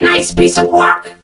mech_crow_kills_01.ogg